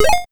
ChipTune Arcade FX 03.wav